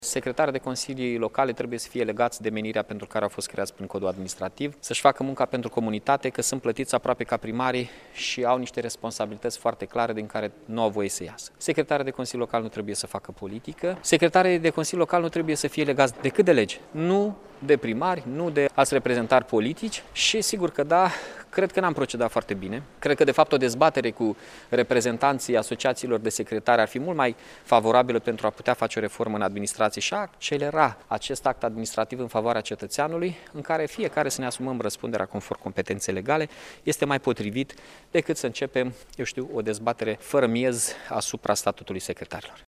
Pe de altă parte, în aceeași conferință de presă, primarul Iașului, Mihai Chirica, și-a exprimat dezacordul față de intenția de modificare a Legilor Administrațiie Publice Locale prin care se dorește ca mandatul persoanelor care vor ocupa funcția de secretar al Consiliului Local să coincidă cu cel al primarului în funcție.